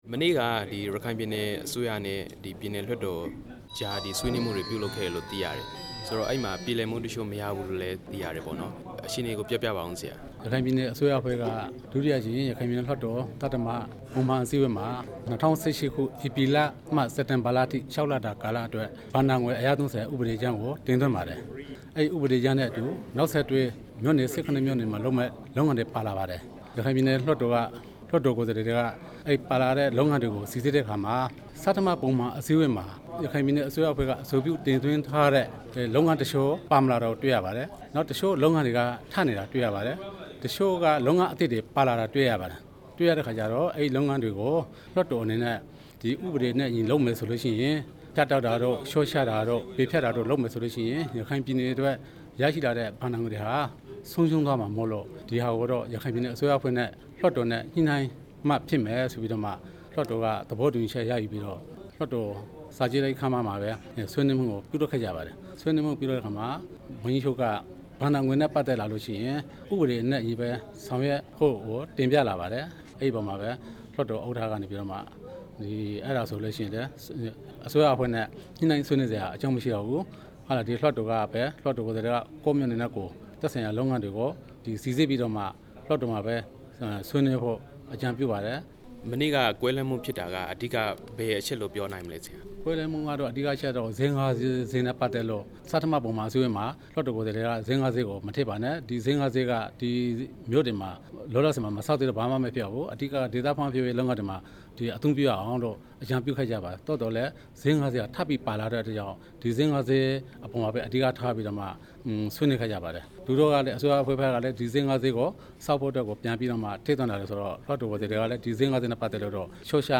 ရခိုင်ပြည်နယ်လွှတ်တော် ဒုတိယဥက္ကဌ ဦးမြသန်း
မေးမြန်းထားပါတယ်။